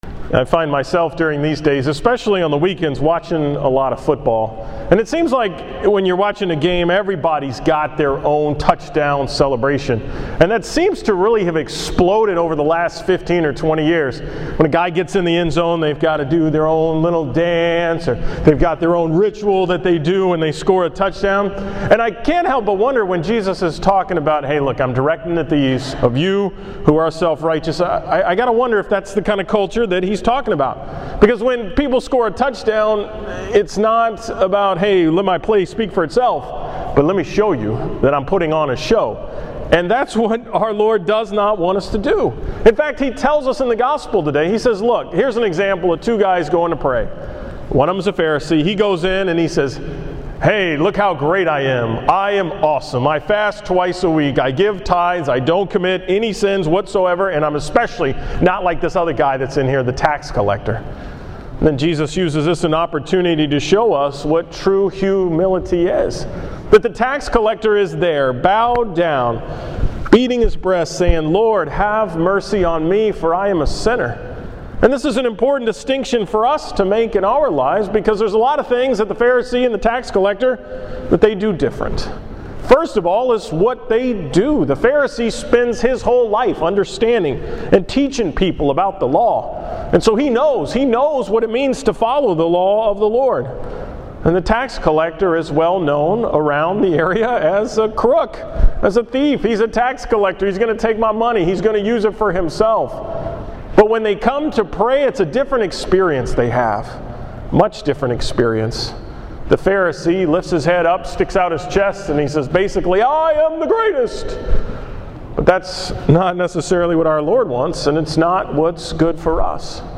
Category: 2013 Homilies